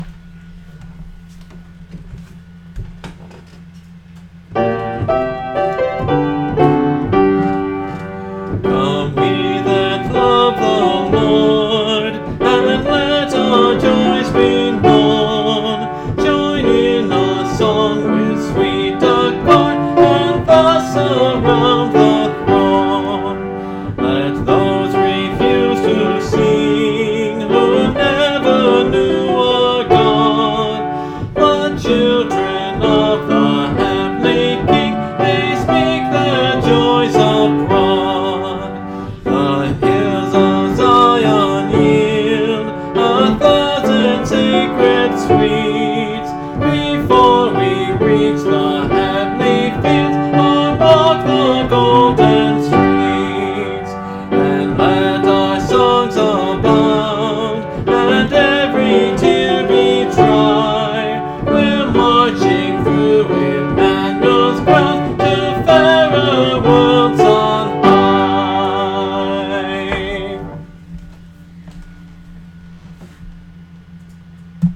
(Part of a series singing through the hymnbook I grew up with: Great Hymns of the Faith)
This hymn is a very familiar hymn.
It has a straight message, encouraging, evokes Biblical images, with a rousing, rejoicing unison of lyric and music.